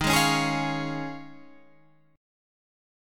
Eb7sus2sus4 chord